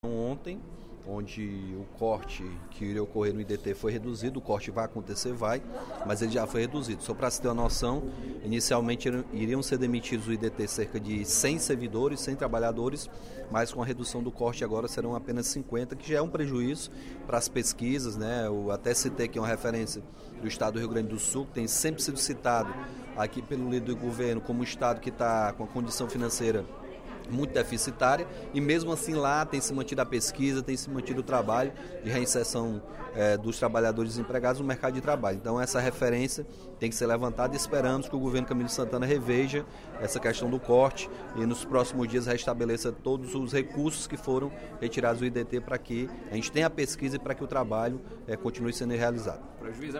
O deputado Capitão Wagner (PR) reforçou, durante o primeiro expediente da sessão plenária desta quarta-feira (08/03), a importância do Sistema Nacional de Emprego e Instituto de Desenvolvimento e Trabalho no Ceará (Sine/IDT).